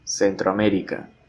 or Centroamérica [sentɾoaˈmeɾika]
Es-pe_-_Centroamérica.ogg.mp3